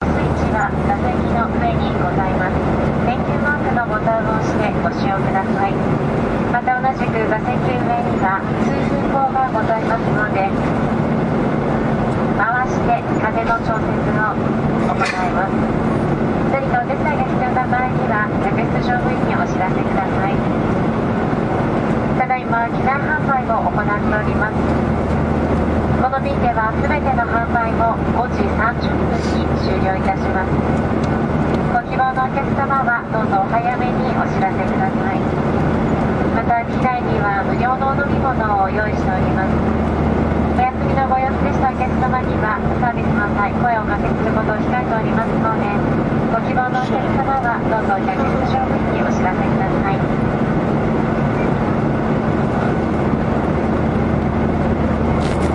试点公告
描述：一名飞行员宣布飞机抵达。
标签： 飞行员 客舱 飞机 飞机 飞机 飞机 船员 飞行 气道 喷射 飞行 公告